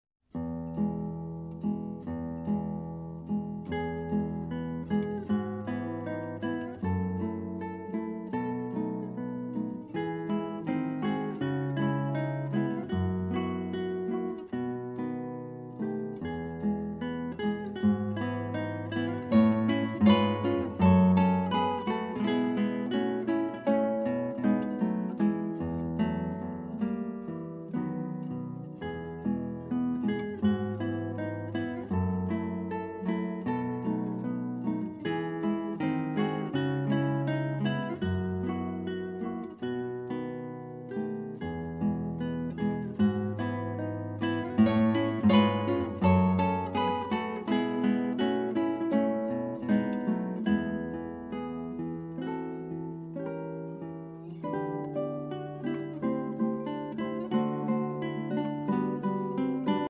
Guitarist